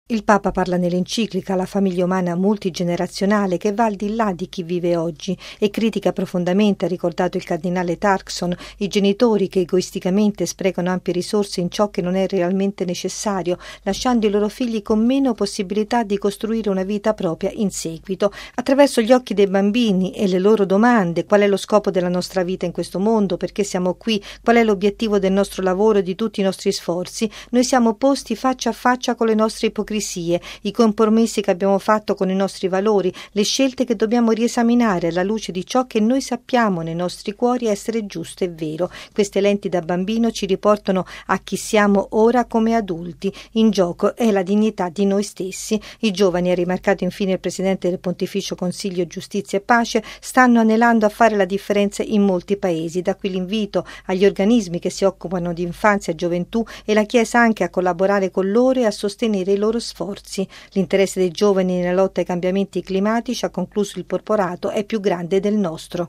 Un testo che tanto più si apprezza se letto attraverso gli occhi dei bambini, così come ha fatto il cardinale Peter Turkson, presidente del Pontificio Consiglio Giustizia e Pace, in un incontro dedicato alle agenzie che si occupano di infanzia, ospitato oggi nella sede dell’Unicef, a New York. Il servizio